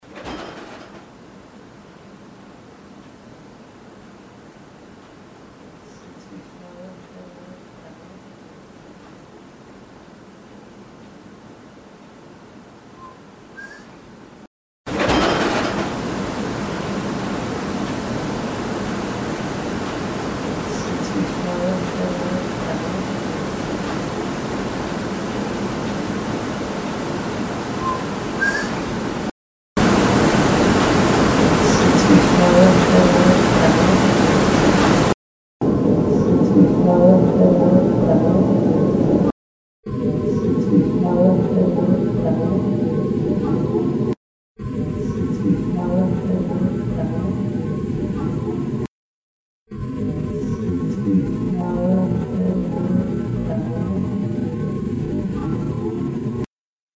Knowing this house was inhabited by at least one spirit, we brought along a digital camera and the Sony audio recorder.
It is played once as recorded, once amplified, once more with just the EVP, and again with noise reduction. The final portion of the clip has even more noise reduction plus it is slowed down.
I do not recognize the voice.
There are more words after the last but they're too low to hear.